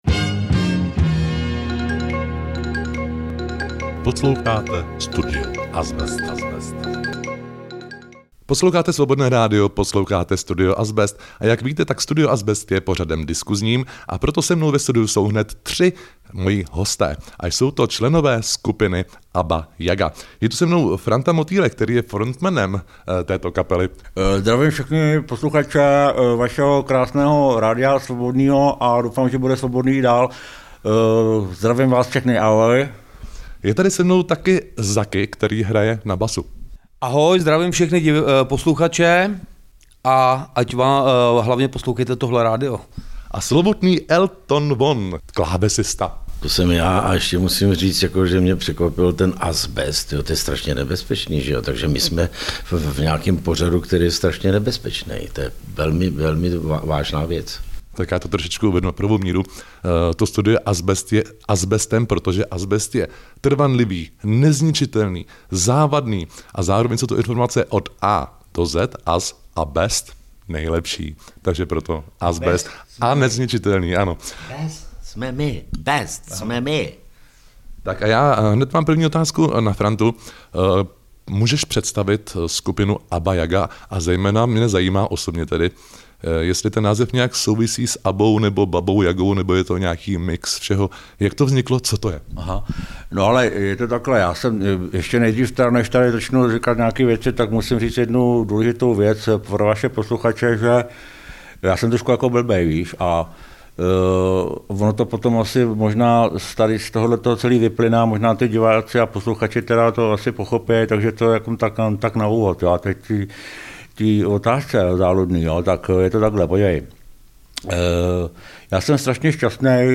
2026-02-27 – Studio AZbest – Nekonvenční povídaní se členy hudební skupiny ABA JAGA